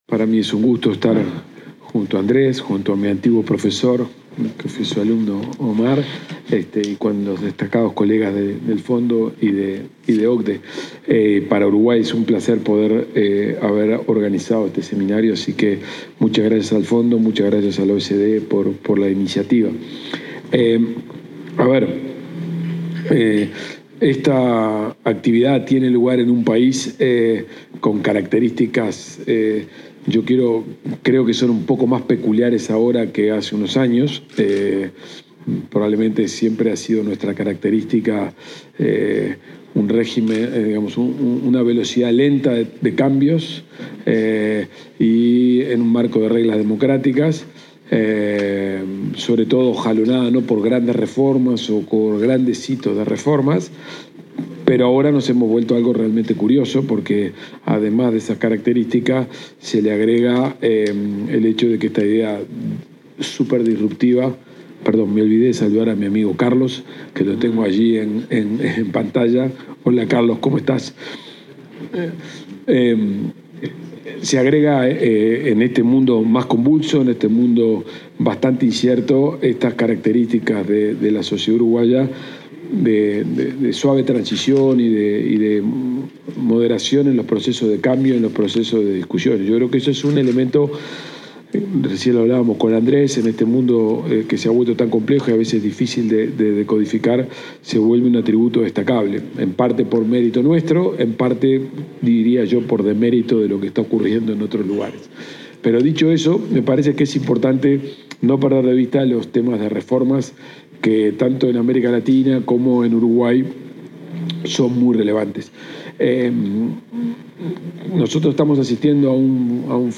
Palabras del ministro de Economía y Finanzas, Gabriel Oddone